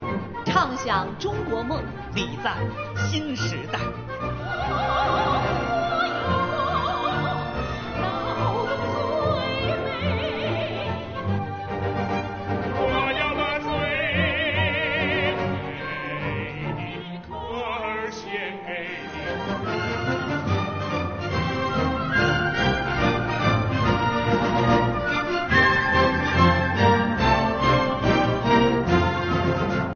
新年交响音乐会
全总文工团
用歌声贺岁 为劳动放歌
音乐会盛况抢先看